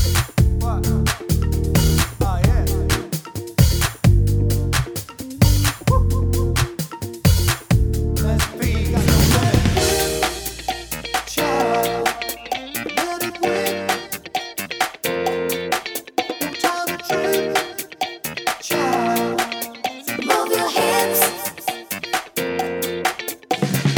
With Intro R'n'B / Hip Hop 4:06 Buy £1.50